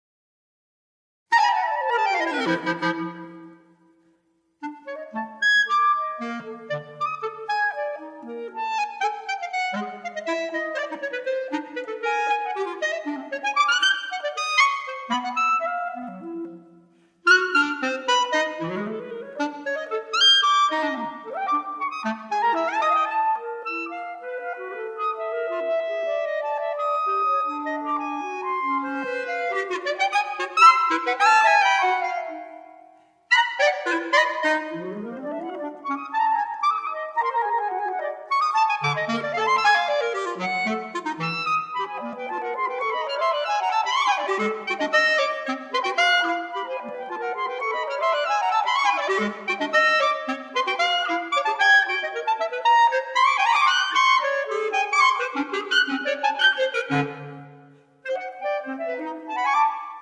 clarinet
E-flat clarinet